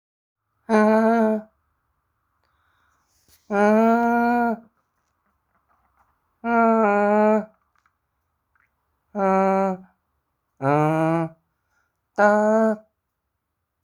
붐뱁 비트 찾아요
일렉기타소리있으면서 잼배?봉고드럼 소리도 났던거같아요